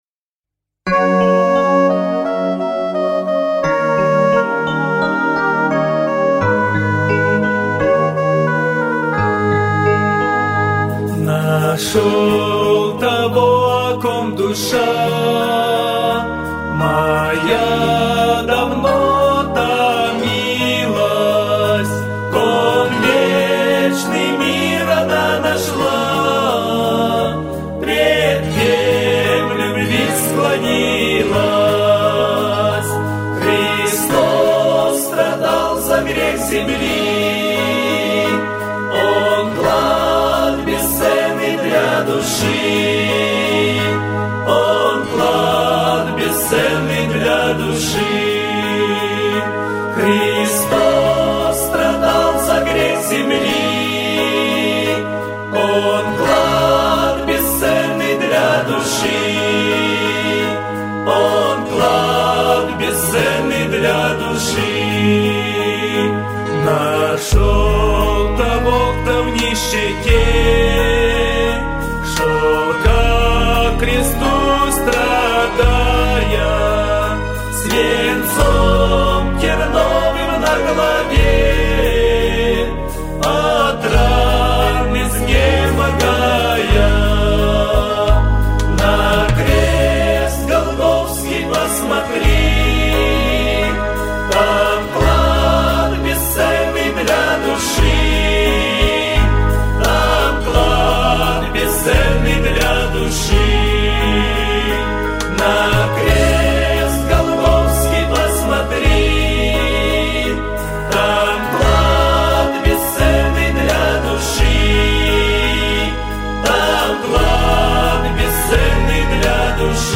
песня
486 просмотров 910 прослушиваний 86 скачиваний BPM: 86